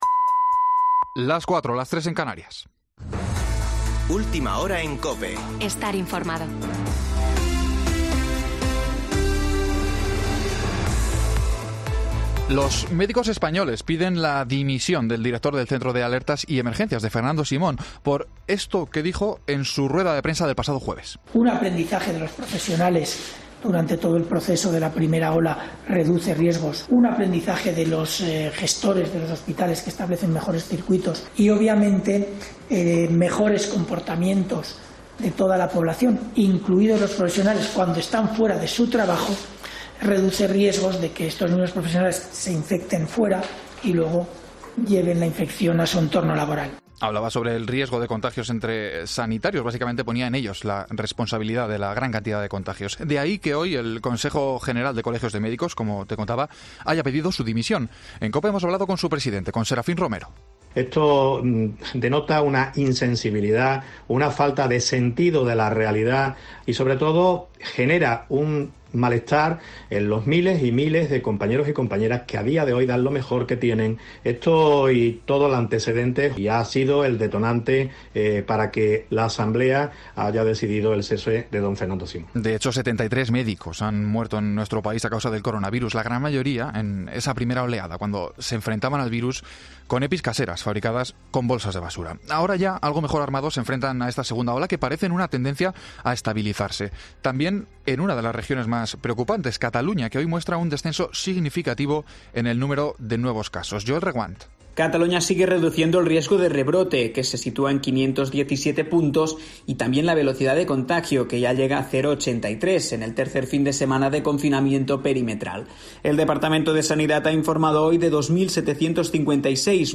Boletín de noticias de COPE del 14 de noviembre de 2020 a las 16.00 horas